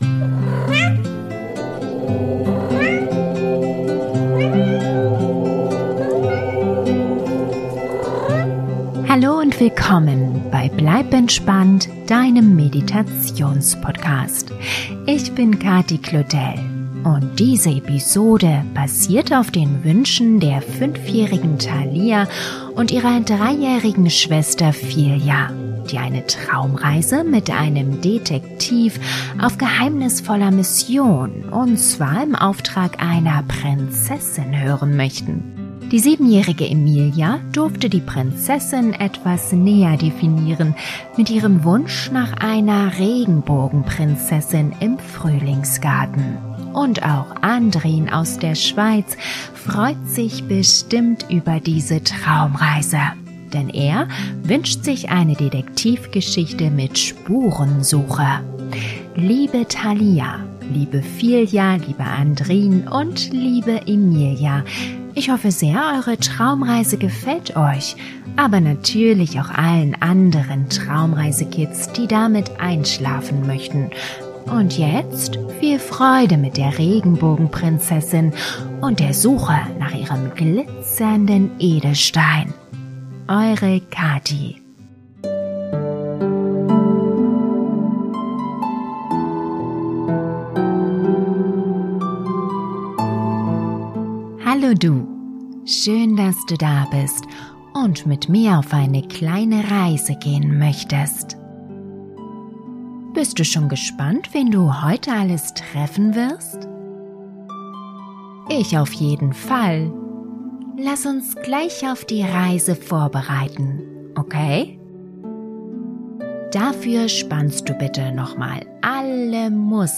Beruhigende Naturgeräusche wie das Summen der Bienen, das Zwitschern der Vögel und das Plätschern von Springbrunnen begleiten die Erlebnisse.